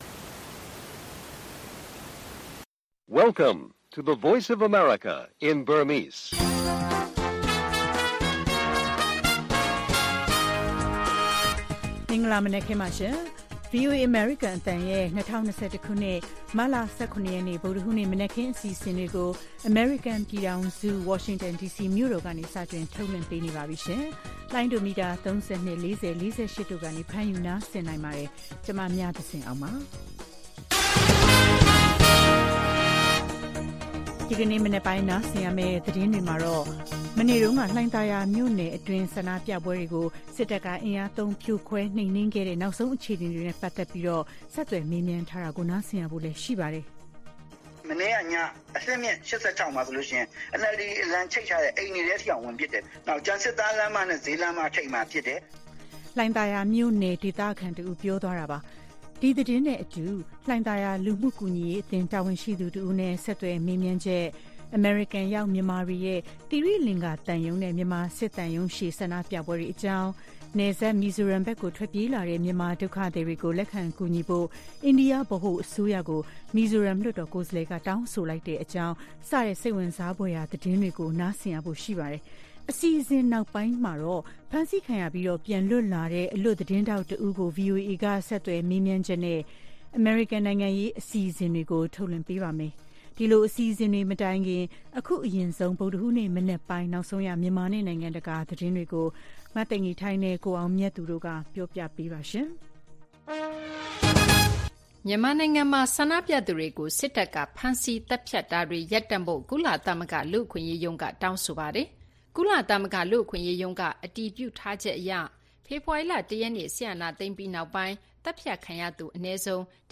ဒီကနေ့အစီအစဉ်မှာတော့ နောက်ဆုံးရနိုင်ငံတကာသတင်းတွေနဲ့အတူ - ရန်ကုန်တိုင်း လှိုင်သာယာ မြို့နယ်တွင်းကနောက်ဆုံးအခြေအနေတွေနဲ့ ပတ်သက်လို့ ဒေသခံတွေနဲ့ ဆက်သွယ်မေးမြန်းချက်၊ အမေရိကန်ရောက်မြန်မာတွေရဲ့ သိရိလင်္ကာသံရုံးနဲ့ မြန်မာစစ်သံရုံးရှေ့ ဆန္ဒပြပွဲတွေ အကြောင်း၊ နယ်စပ် မီဇိုရမ်ဘက်ကို ထွက်ပြေးလာတဲ့ မြန်မာဒုက္ခသည်တွေကို လက်ခံကူညီဖို့ အိန္ဒိယ ဗဟိုအစိုးရကို မီဇိုရမ် လွှတ်တော်ကိုယ်စားလှယ်က တောင်းဆိုလိုက်တဲ့အကြောင်း စတဲ့ စိတ်ဝင်စားဖွယ်ရာ သတင်းတွေကို နားဆင်ရပါမယ်။ အစီအစဉ်နောက်ပိုင်းမှာတော့ ဆန္ဒပြပွဲတွေအတွင်း ဖမ်းဆီးခံရပြီး ပြန်လွတ်လာတဲ့ အလွတ်သတင်းထောက်တစ်ဦးကို VOA က ဆက်သွယ်မေးမြန်းချက်နဲ့ အမေရိကန် နိုင်ငံရေး အစီအစဉ်ကိုလည်း ထုတ်လွှင့်ပေးပါမယ်။